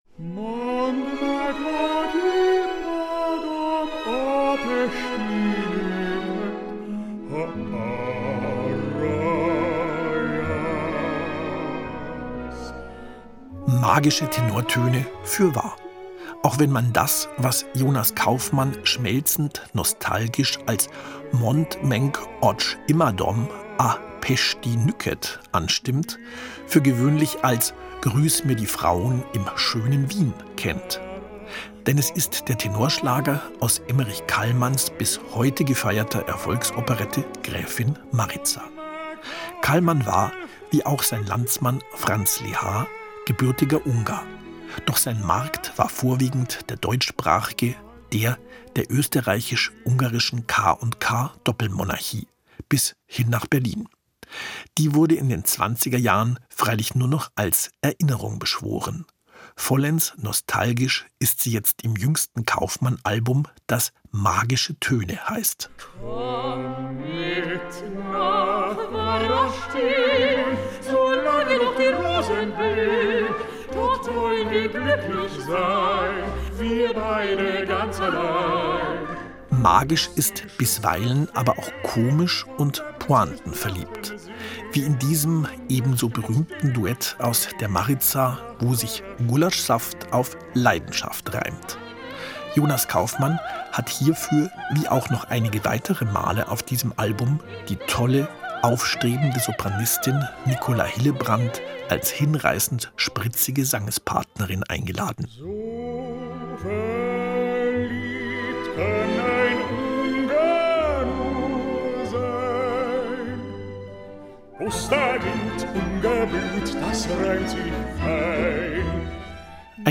Album-Tipp